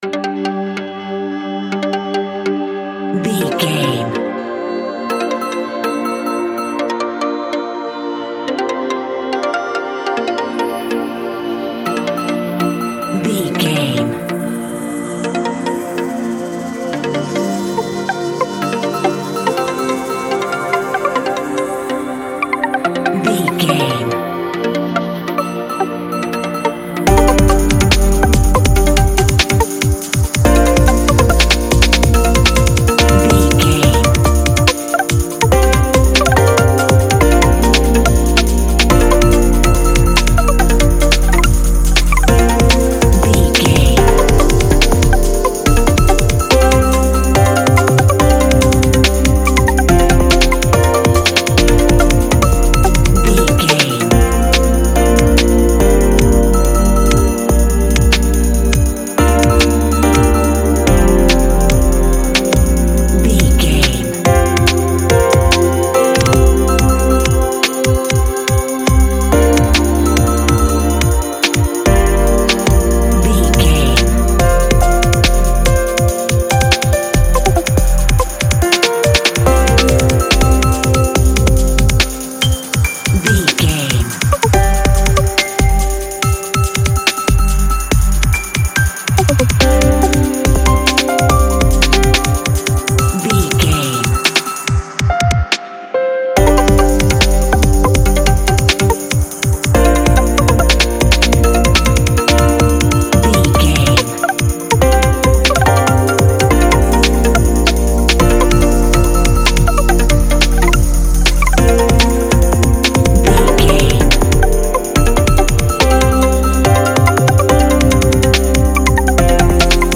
Epic / Action
Fast paced
In-crescendo
Uplifting
Ionian/Major
Asian scale
electronic
ambient
dreamy
synths
hypnotic
percussive
ethereal
atmospheric